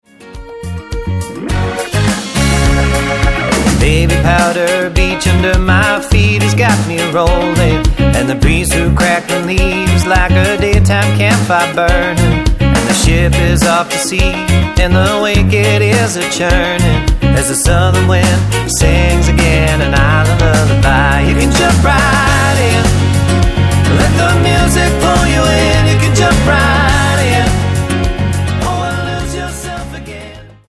Tonart:Db Multifile (kein Sofortdownload.
Die besten Playbacks Instrumentals und Karaoke Versionen .